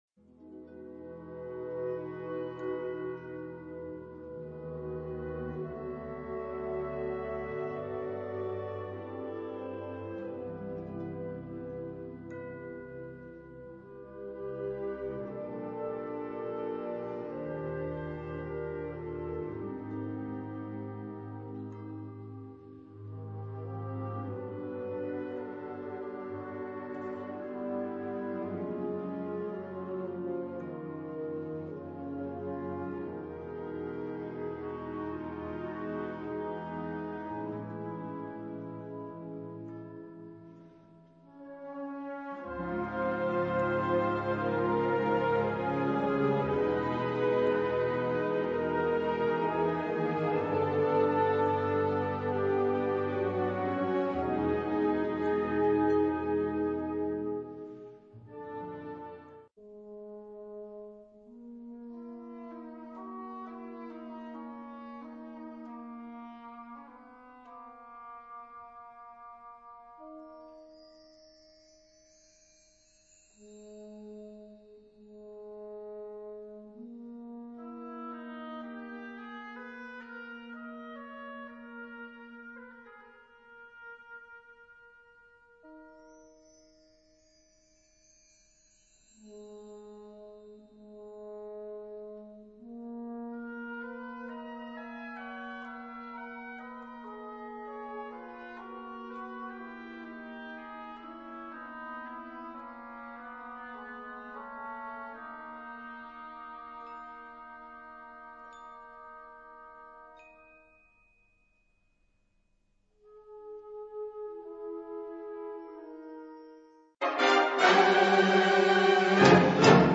Categoria Concert/wind/brass band
Instrumentation Ha (orchestra di strumenti a faito)